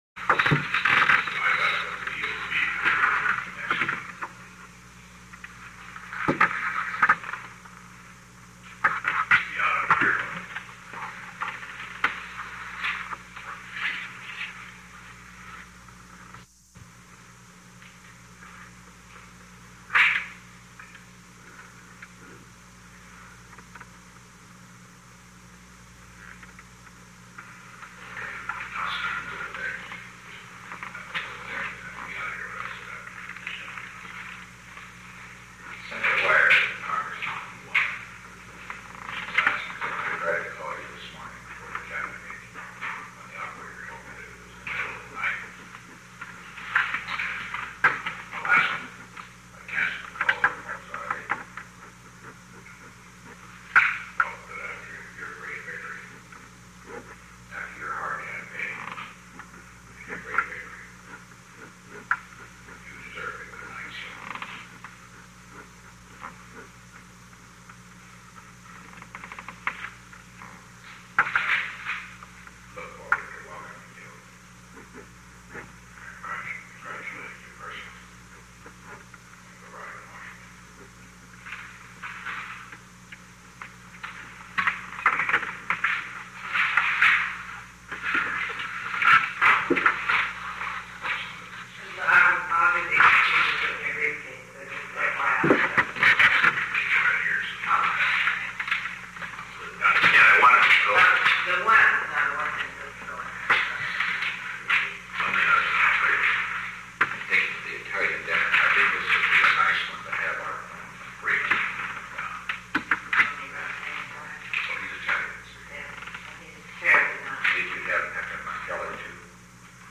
Secret White House Tapes
Conversation No. 874-26
Location: Oval Office